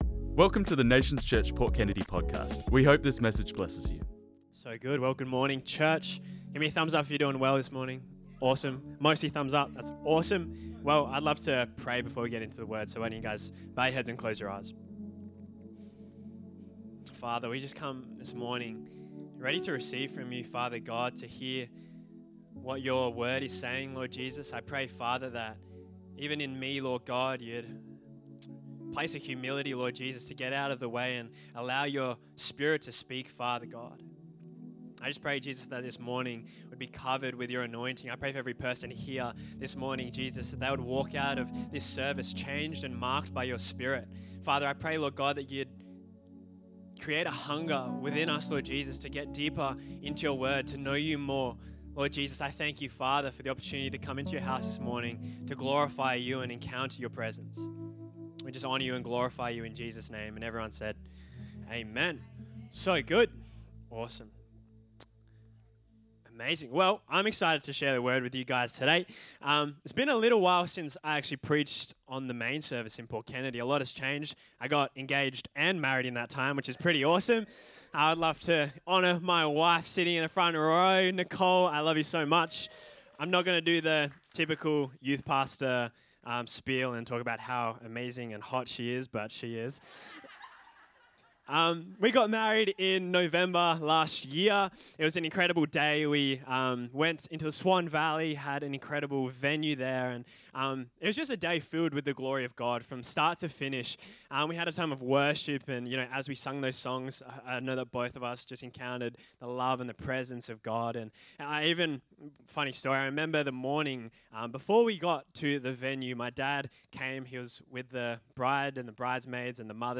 This message was preached on Sunday 12th January 2025